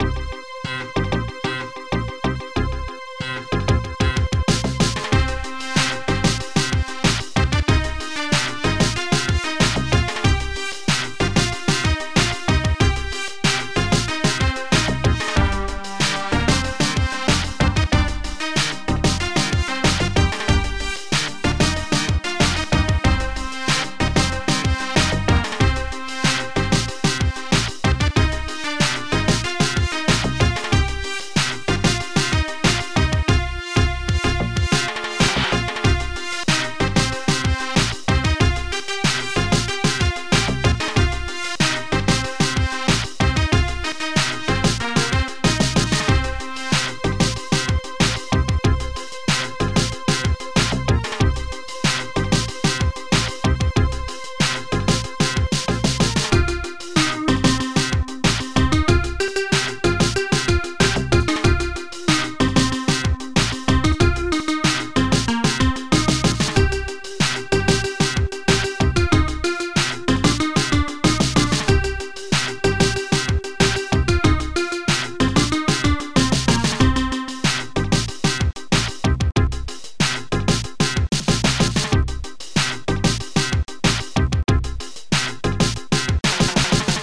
Protracker and family
guitar.bolie
snr+clap.techno
trumpet.trut
guitar.synth